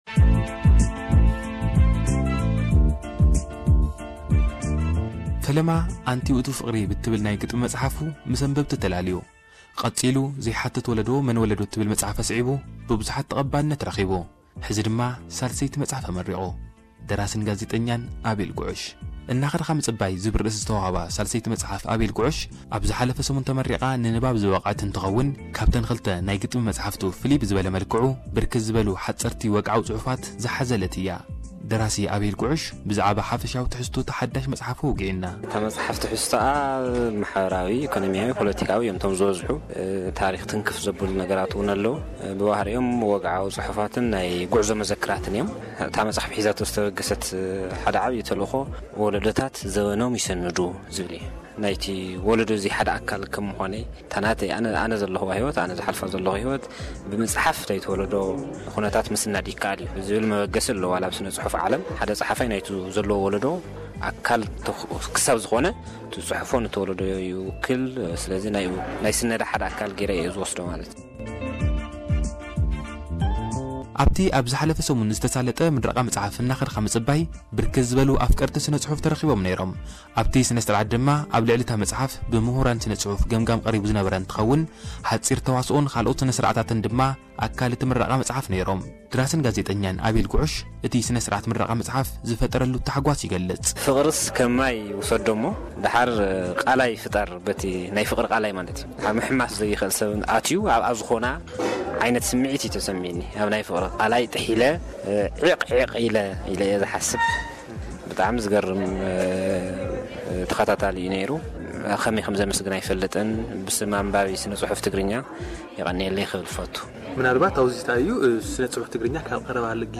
ዝገበሮ ዕላልን ጸብጻብ ስነ ስርዓት ምረቓን እዩ።